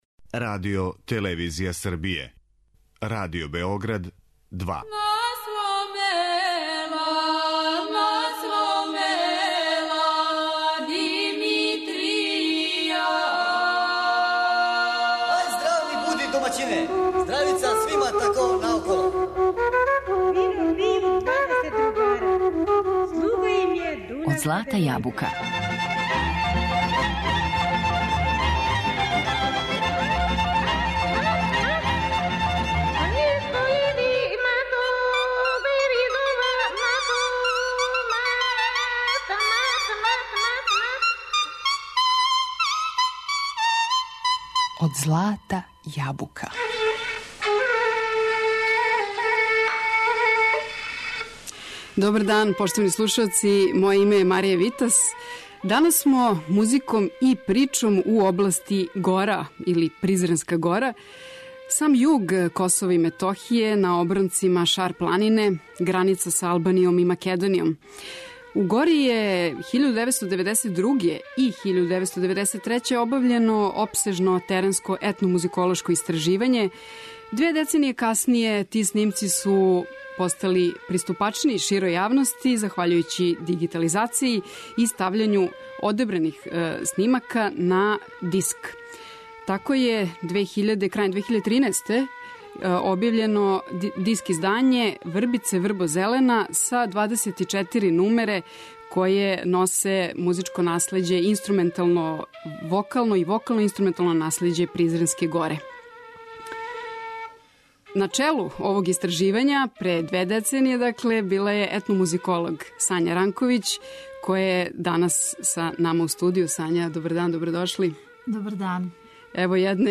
Нумере представљају дигитализоване теренске записе начињене 1992. и 1993. године у седам шарпланинских насеља, да би тек две деценије касније постали приступачни ширем аудиторијуму, у форми диска.